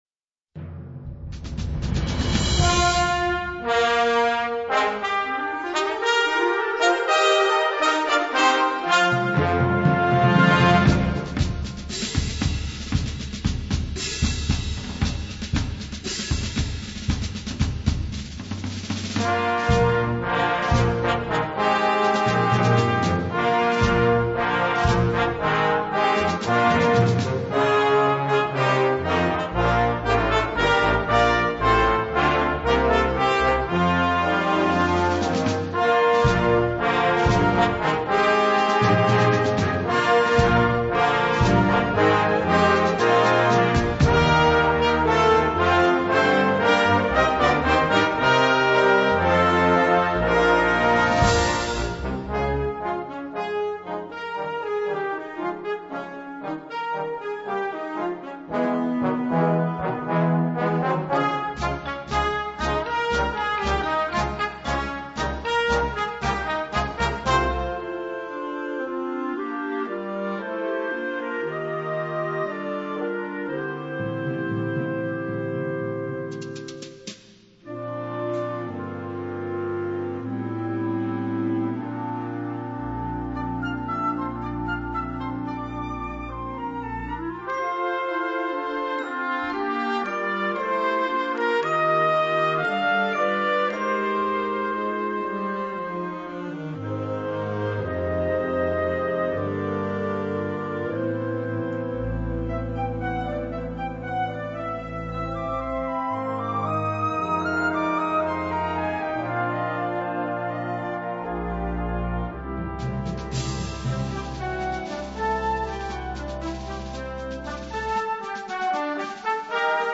Sous-catégorie Marche de concert
Instrumentation Ha (orchestre d'harmonie)